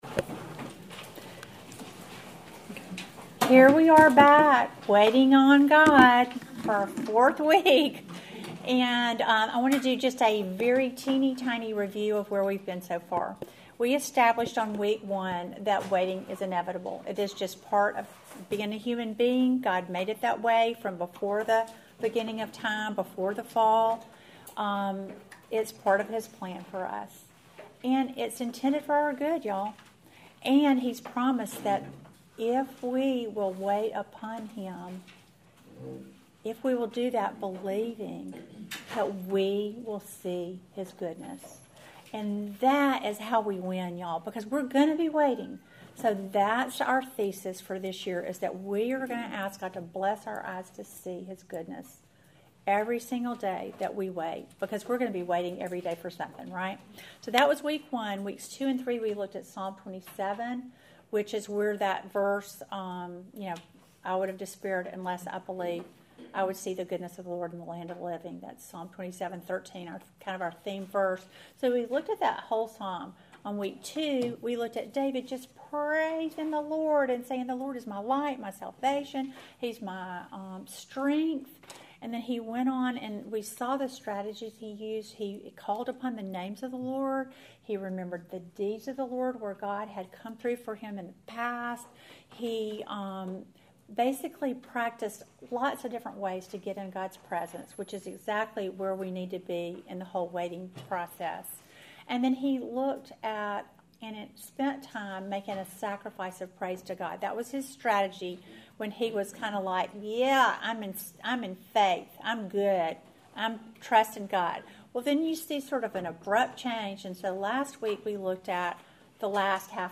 Welcome to the fourth lesson in our series WAITING ON GOD!